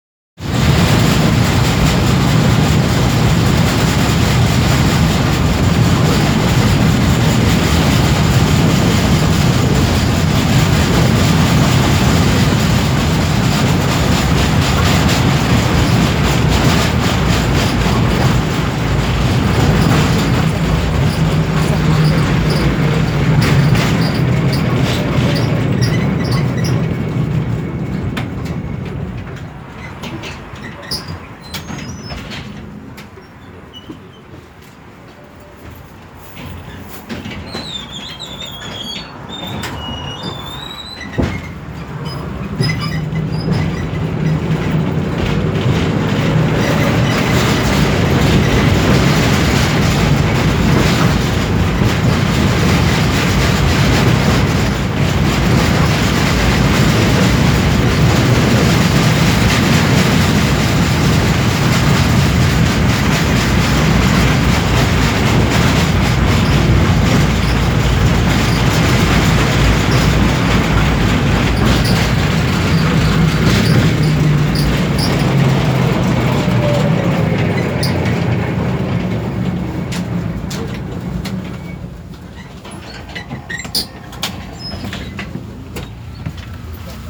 Звуки трамвая
Звук трамвайного салона